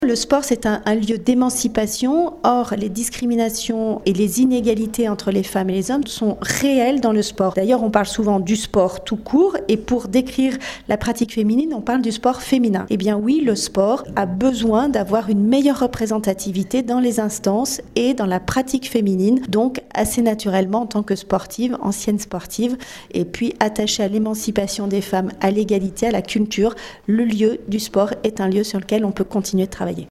La députée d’Annecy, et donc co-rapporteure de l’évaluation de la loi, Véronique Riotton s’en explique